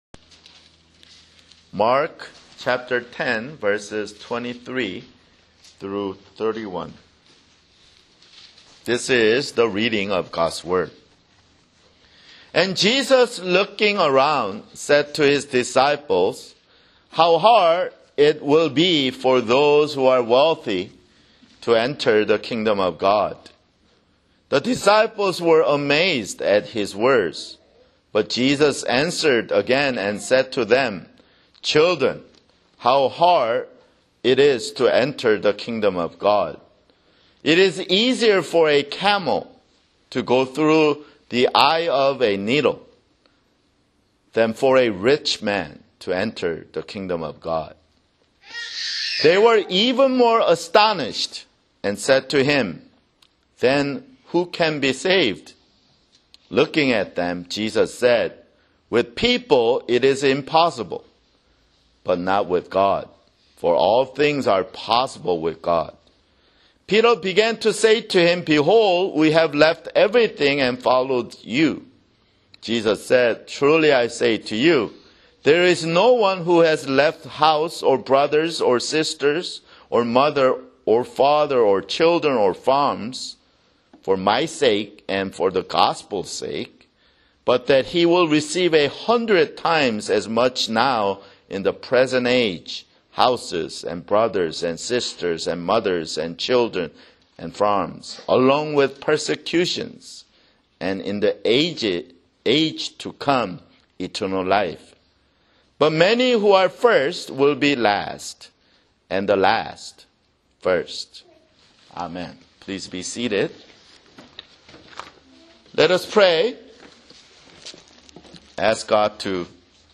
[Sermon] Mark 10:23-31
Download MP3 (Right click on the link and select "Save Link As") Labels: Sermon - Lord's Supper